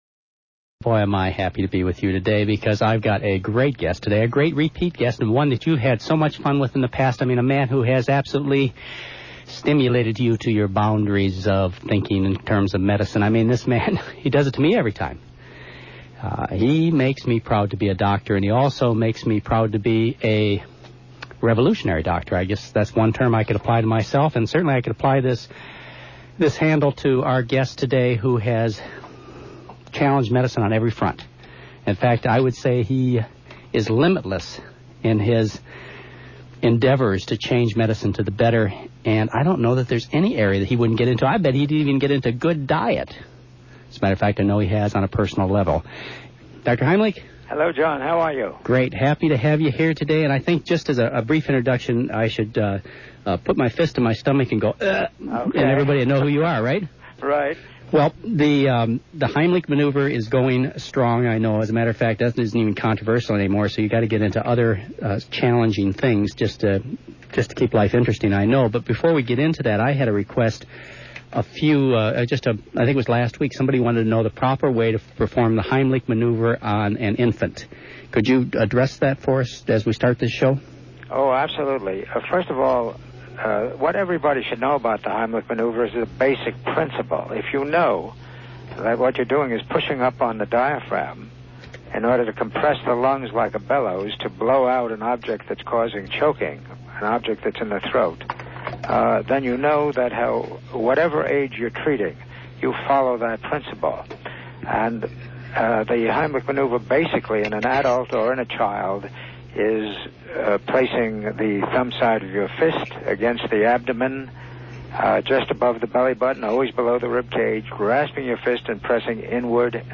Henry Heimlich, MD (taped interview)
In this 1991 interview, Dr. McDougall talks with one of his favorite guests, Henry Heimlich, MD, best known for the lifesaving “Heimlich maneuver,” which has been shown to be effective in saving drowning victims, asthma sufferers and cystic fibrosis patients. Heimlich, the president of the Heimlich Institute Foundation in Cincinnati, Ohio, discusses the history and evolution of the Heimlich Maneuver, as well as his foundation’s work with Lyme disease and “malariotherapy,” the practice of intentionally infecting a patient with malaria in order to treat another ailment.
Note: This taped interview from “Your Good Health” (12/12/91), hosted by Dr. McDougall has been edited.